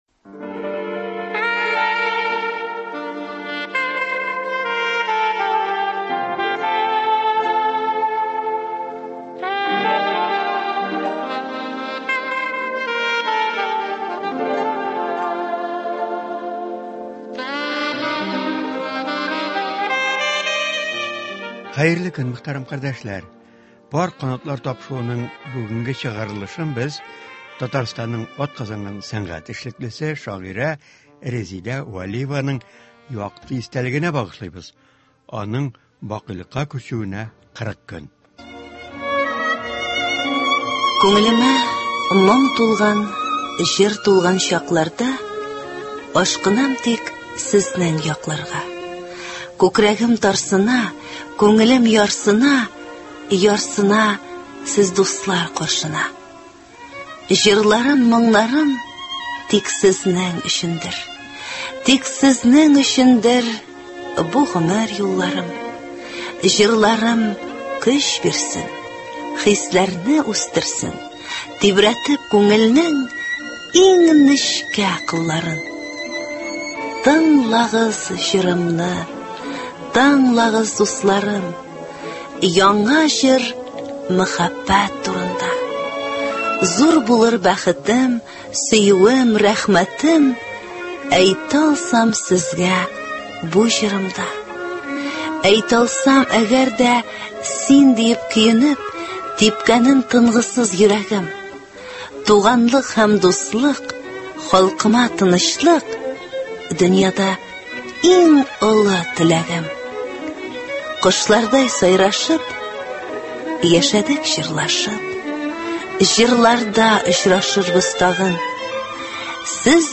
үзе һәм танылган артистлар башкаруында шигырьләр бирелә.